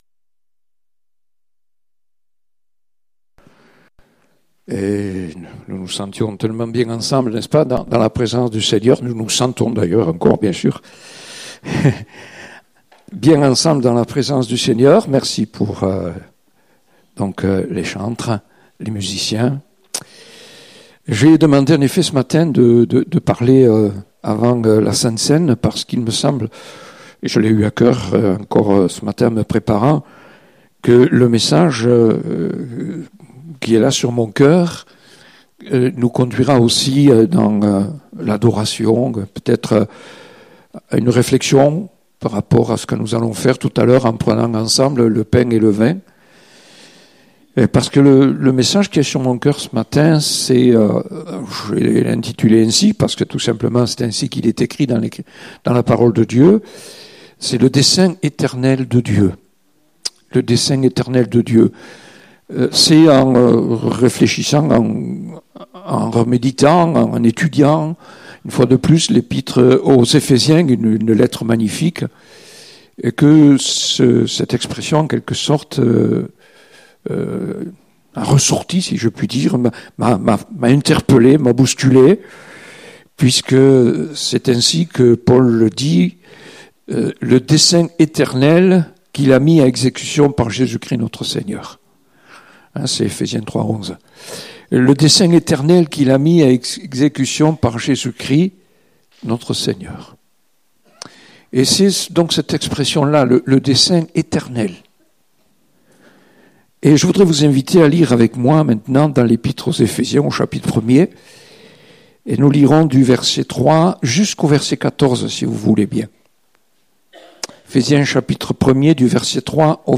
Date : 17 février 2019 (Culte Dominical)